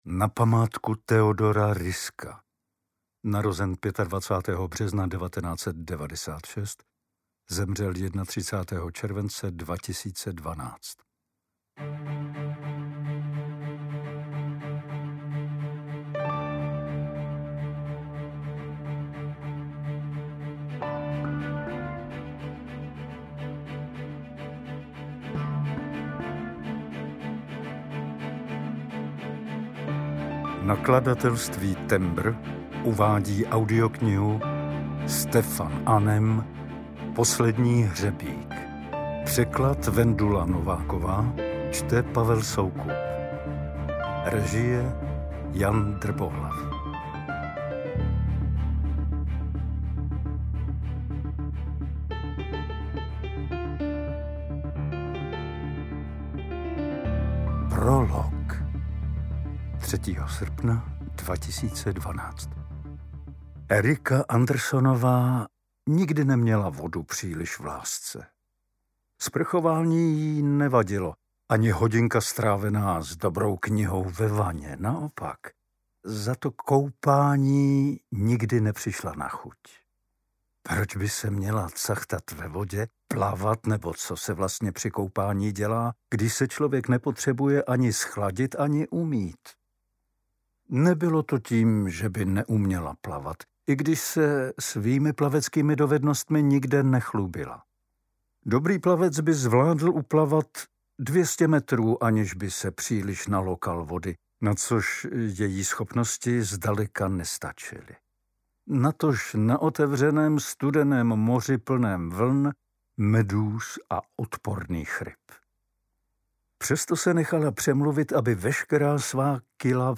UKÁZKA Z KNIHY
Čte: Pavel Soukup
audiokniha_posledni_hrebik_ukazka.mp3